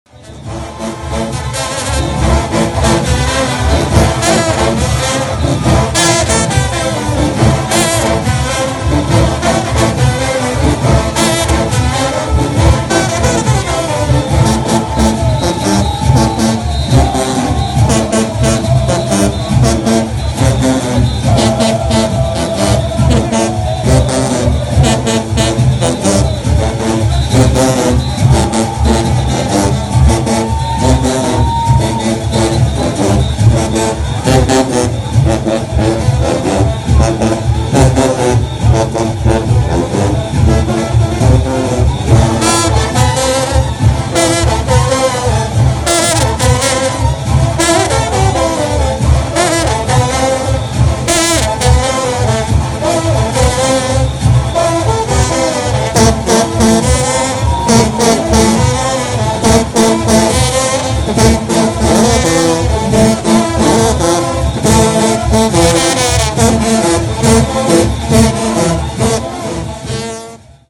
Boxing-Day-2017-In-the-Gate-AUDIO.mp3